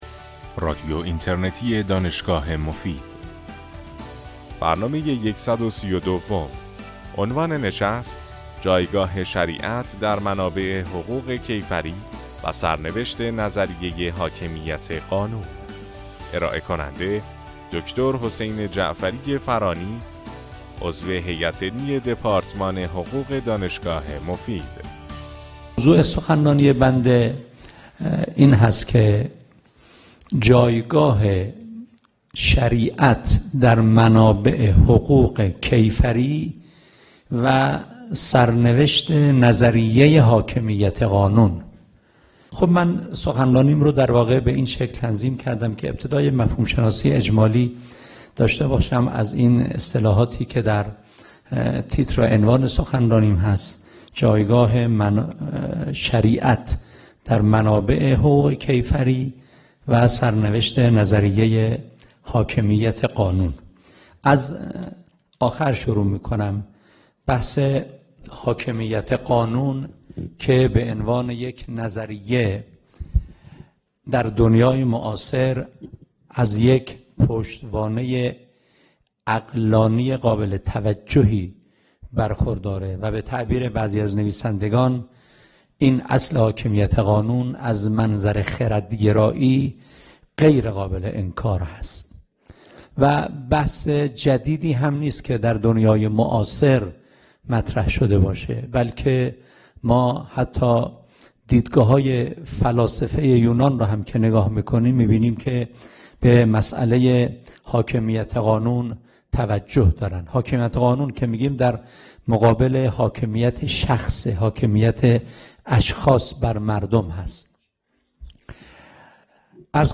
در این سخنرانی که در سال 1398 ایراد شده است